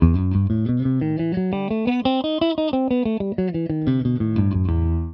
exemple audio "mi mineur harmonique"
Gammes guitare mode mineur harmonique
1-Mi_mineur_harmonique.wav